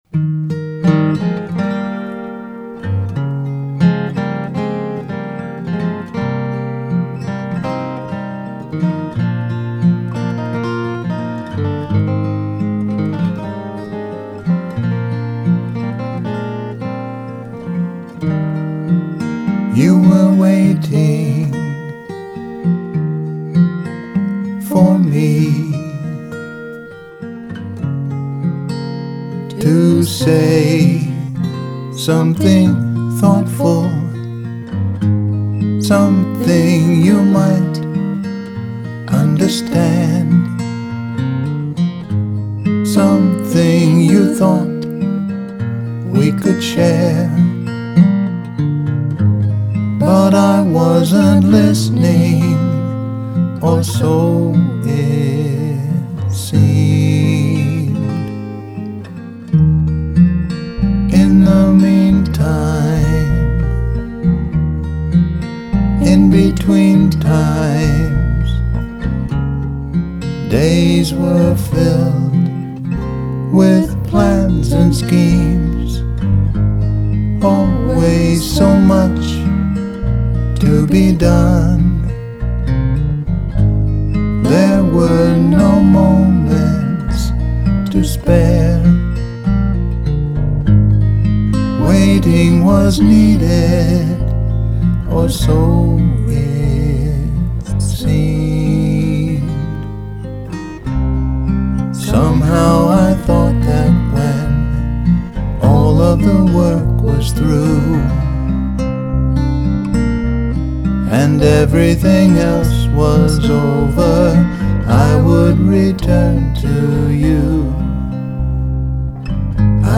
guitar and vocals